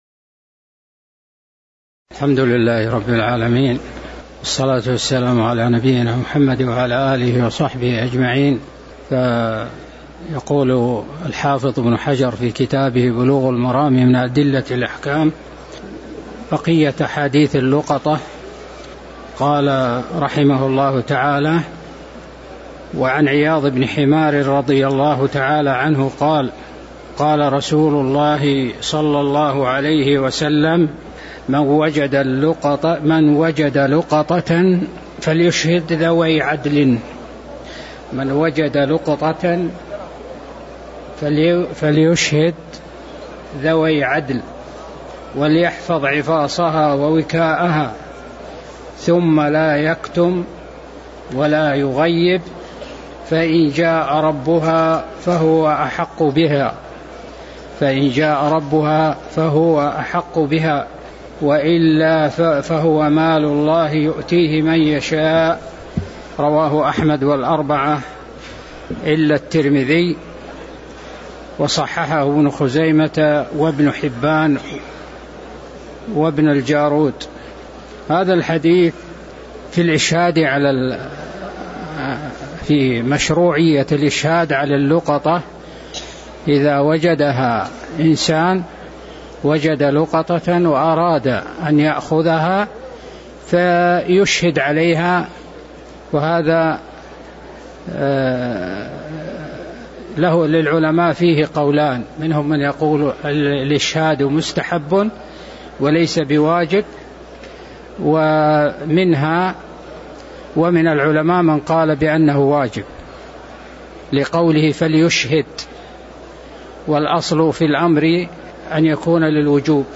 تاريخ النشر ٥ شعبان ١٤٤٠ هـ المكان: المسجد النبوي الشيخ